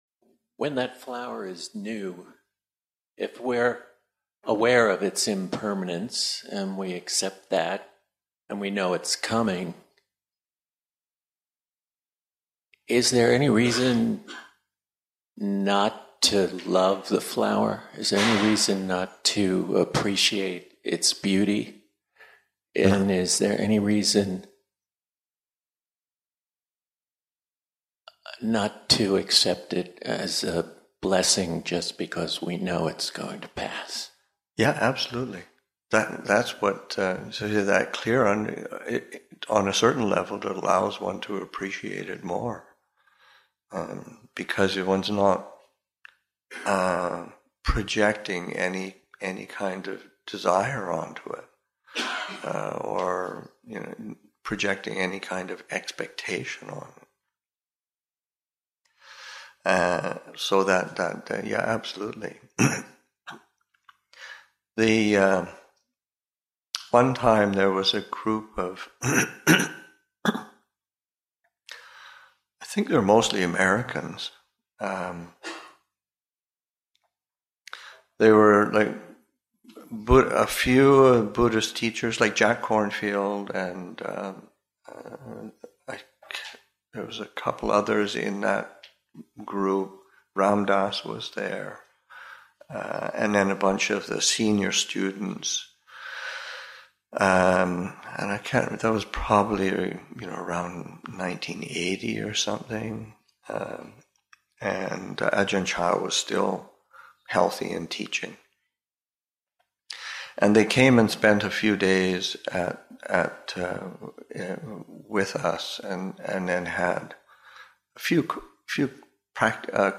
Interreligious Retreat-Seminar on Dhamma and Non-duality, Session 1 – Nov. 24, 2023